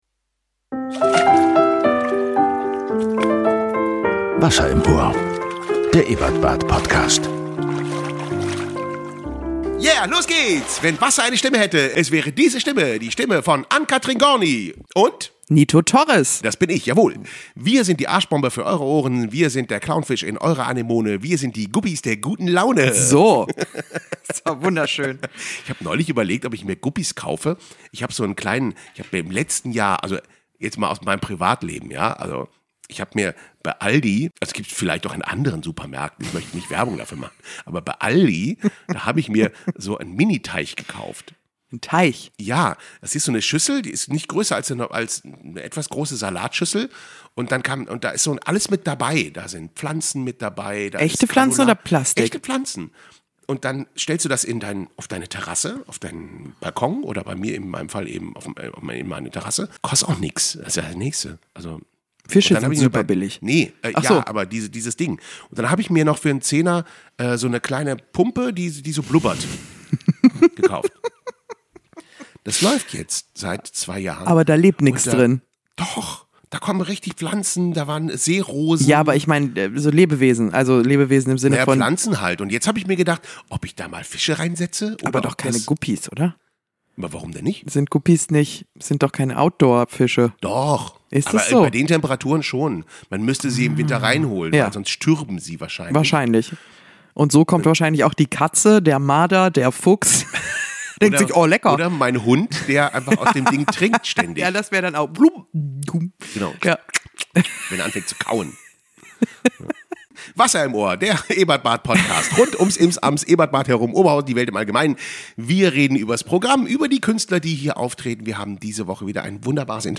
Im Interview: Dagmar Schönleber Paarungsexzesse beim Beuteltier, kein Guppi im Teich, Moosprofessur und sehr alte Lebewesen, Ästhetik ist immer eine Frage der richtigen Schnitttechnik